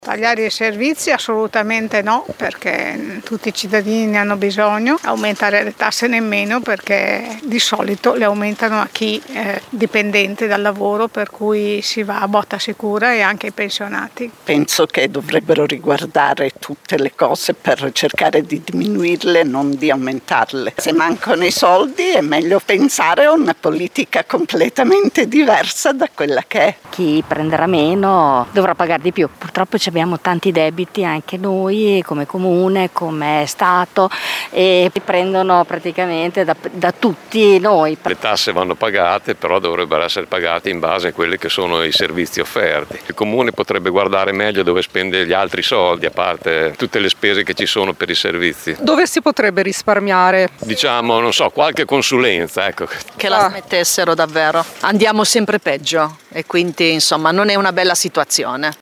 Cittadini sempre più tartassati, sentiamo come hanno accolto la notizia i modenesi
VOX-AUMENTI-TASSE.mp3